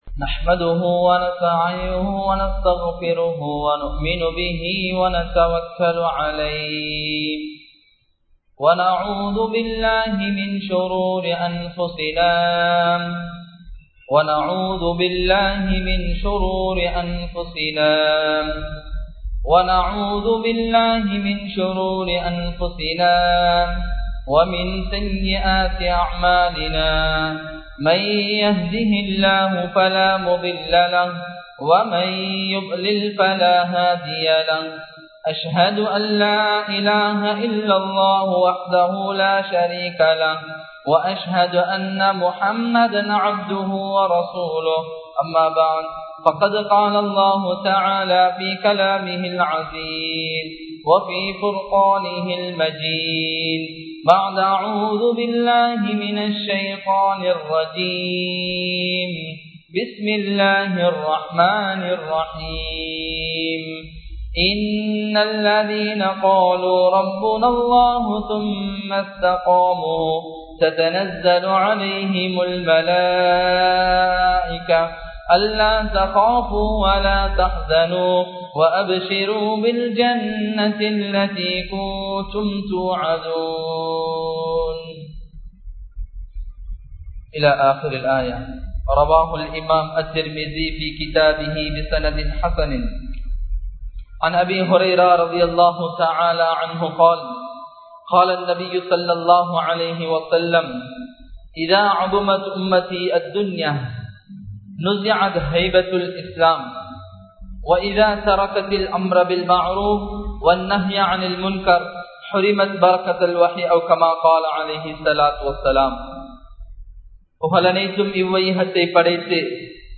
அசாதாரண சூழ்நிலையின் போது (At the Emergency Situation) | Audio Bayans | All Ceylon Muslim Youth Community | Addalaichenai
7th Mile Post Jumua Masjith